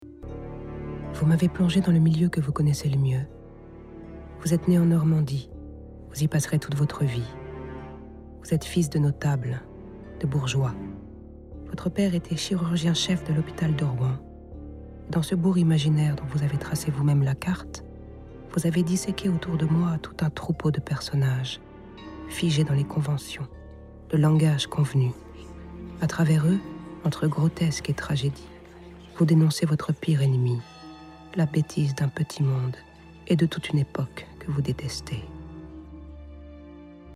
Narration Doc, Affaire Bovary de S.Miquel et A. Vian